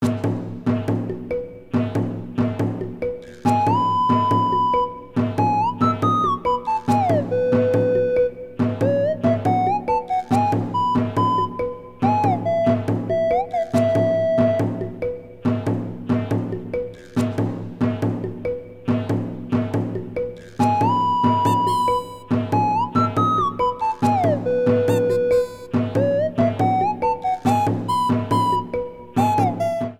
Trimmed file to 30 seconds, applied fadeout